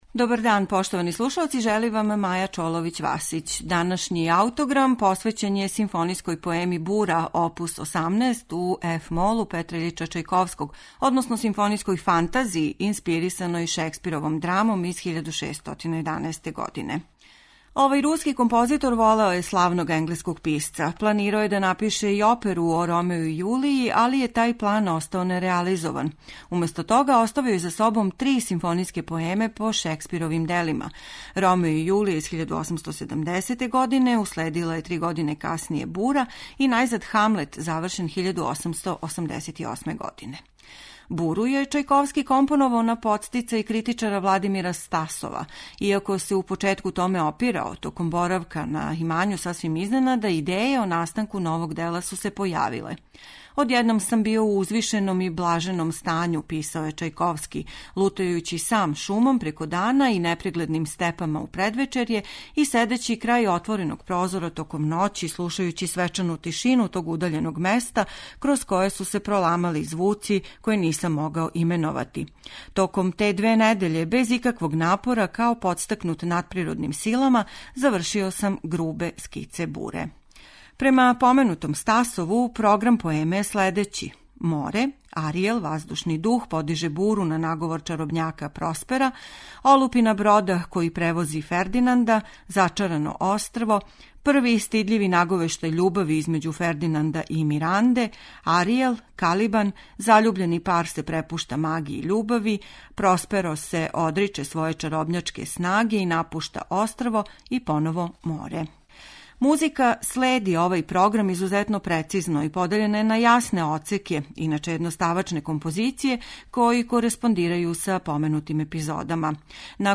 Симфонијску фантазију Бура оп. 18 компоновао је Чајковски 1873. године инспирисан Шекспировом драмом на предлог Владимира Стасова.